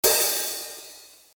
今回は、あらかじめ用意した HiHat 音を使います。
Open あるいは、ハーフOpenぎみの音です。
Hat_03.mp3